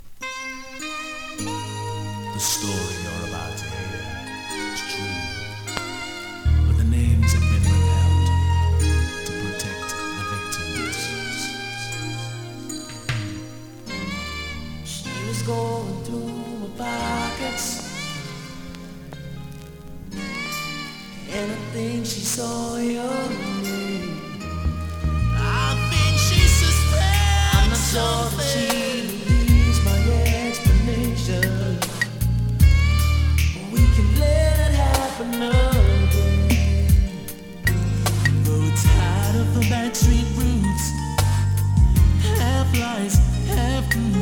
DANCEHALL!!
スリキズ、ノイズかなり少なめの